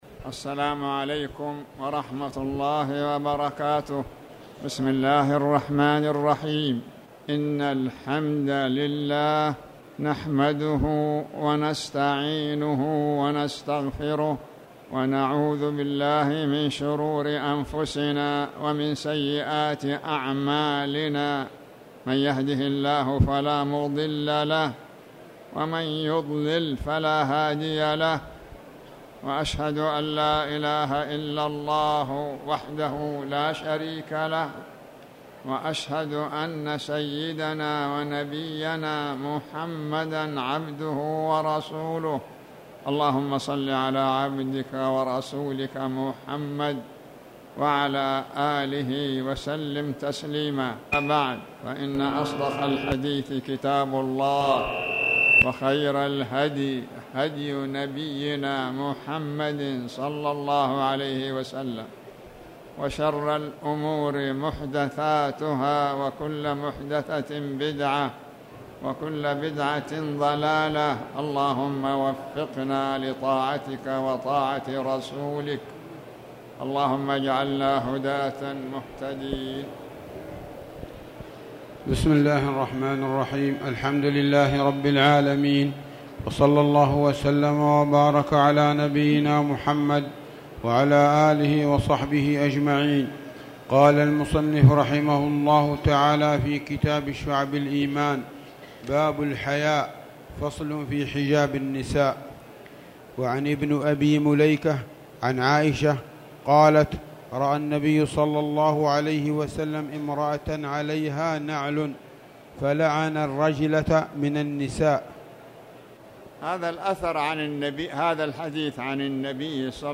تاريخ النشر ١٠ رجب ١٤٣٩ هـ المكان: المسجد الحرام الشيخ